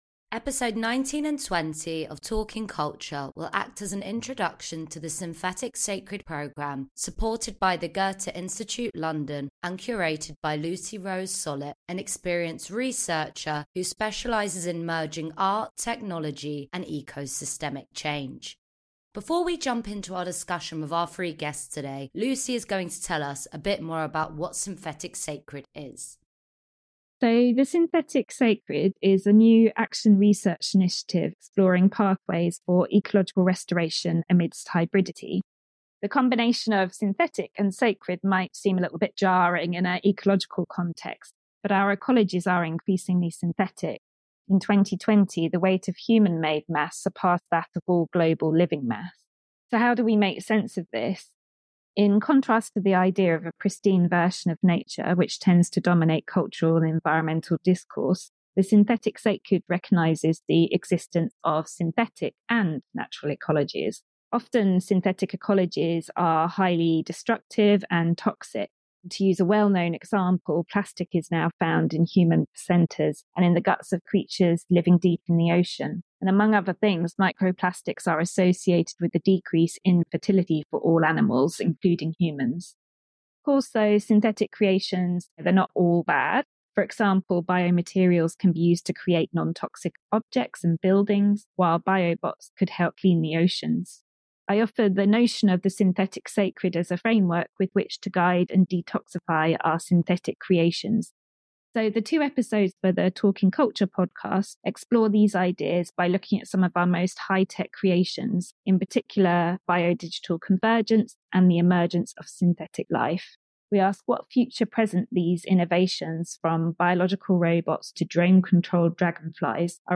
Through fascinating interviews with thinkers and doers in the arts and culture sector, this show investigates how creative fields are emerging from the tumultuous present into the future.